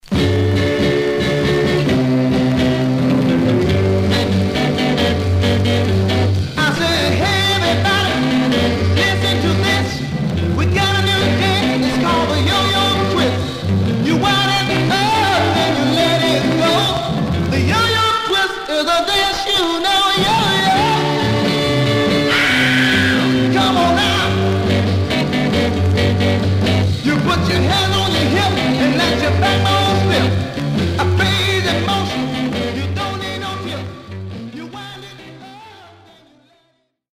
Surface noise/wear Stereo/mono Mono
Funk